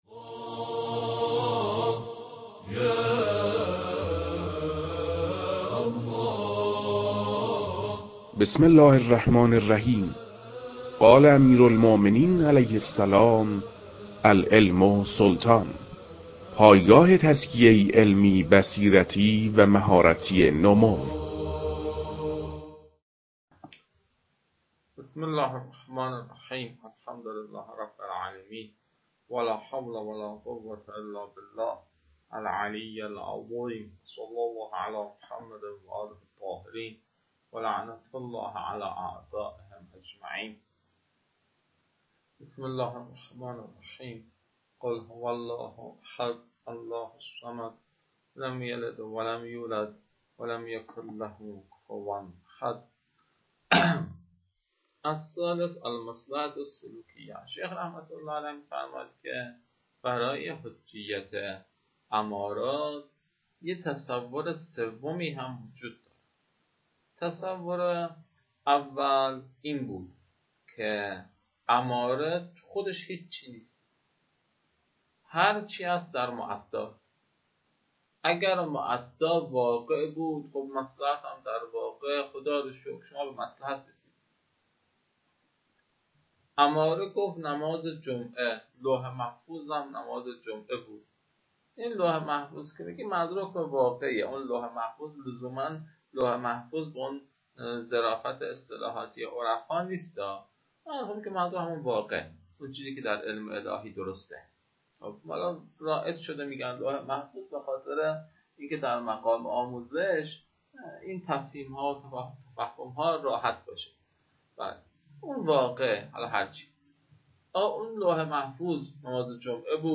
در این بخش، فایل های مربوط به تدریس مبحث رسالة في القطع از كتاب فرائد الاصول متعلق به شیخ اعظم انصاری رحمه الله